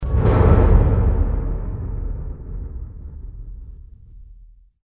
GOLPE FONDO 330
Ambient sound effects
Golpe_fondo_330.mp3